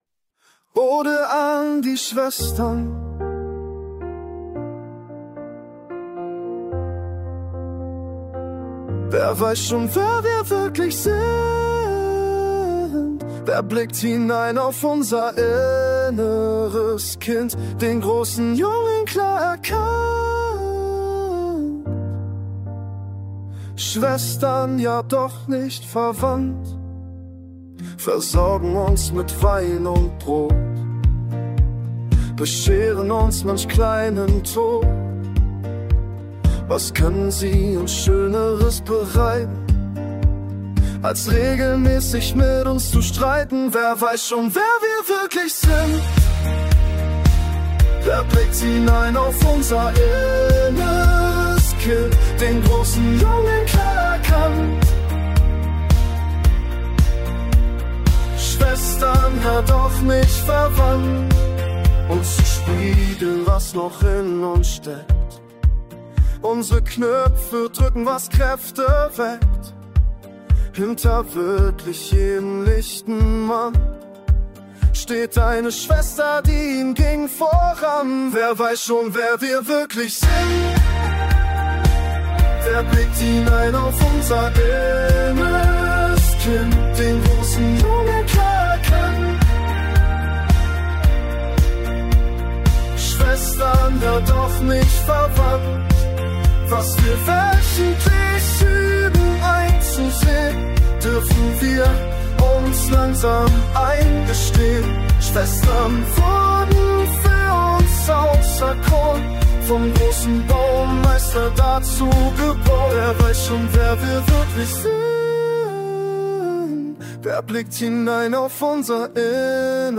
Modern Pop